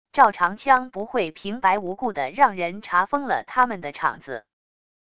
赵长枪不会平白无故的让人查封了他们的厂子wav音频生成系统WAV Audio Player